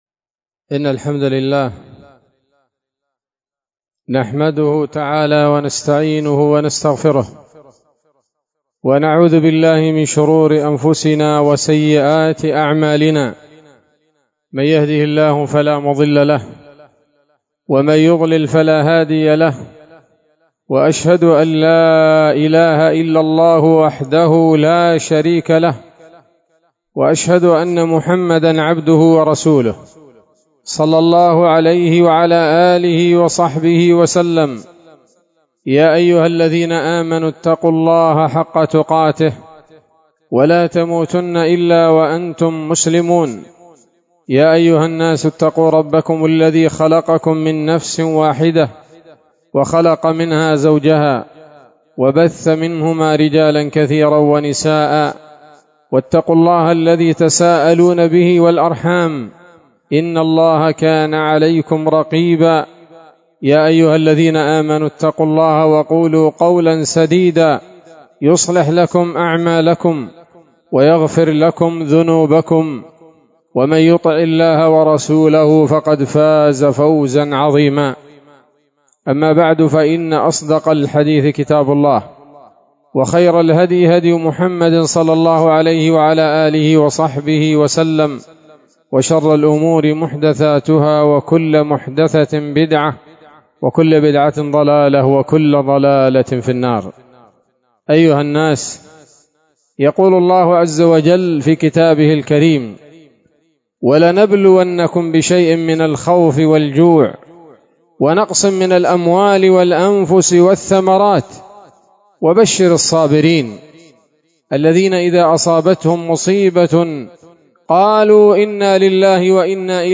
خطبة جمعة بعنوان: (( غلاء الأسعار.. الأسباب والحلول )) 14 صفر 1447 هـ، دار الحديث السلفية بصلاح الدين